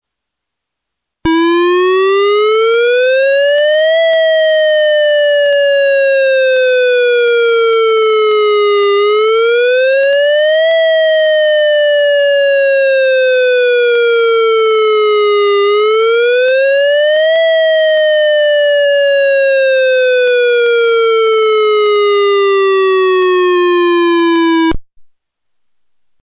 1. ‘Wail’ tone (Telford Fire)
No Voice Message – Tone Only
wail.mp3